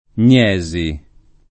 [ + n’ %S i o + n’ $@ i ]